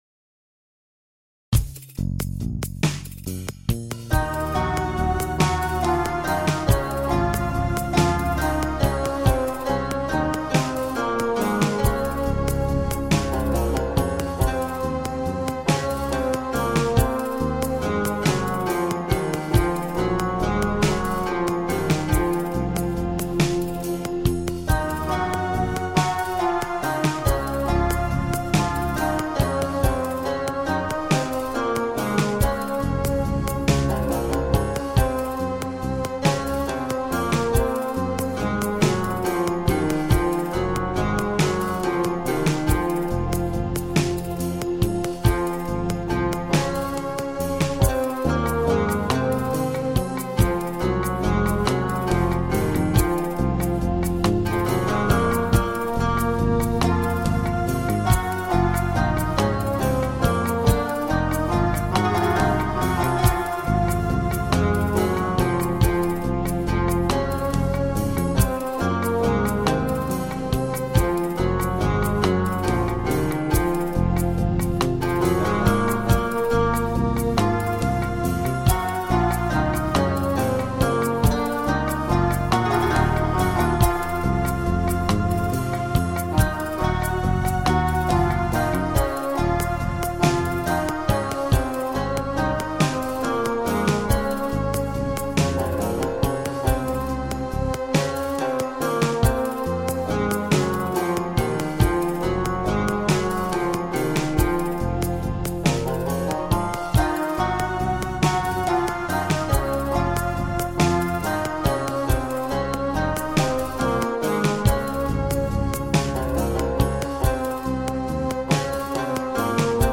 featuring a brand-new drum kit